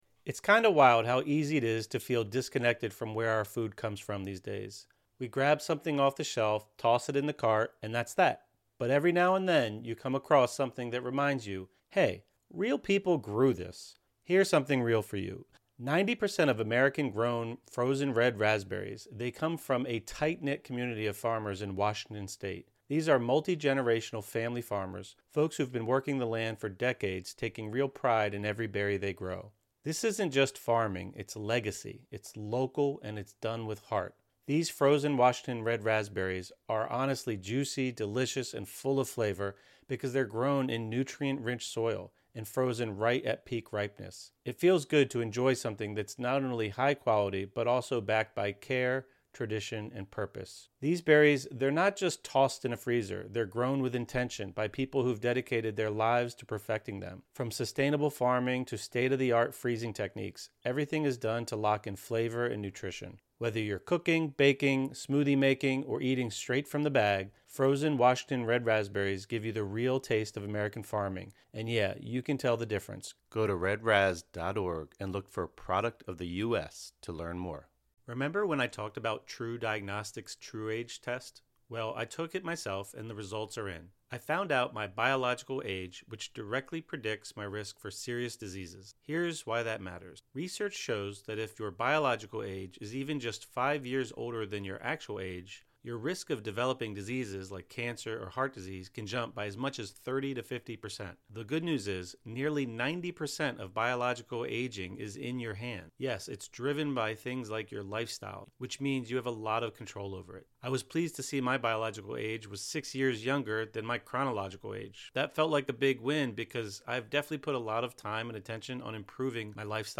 20 Minute Mindfulness Meditation | Come Home to Your True Self (; 12 May 2025) | Padverb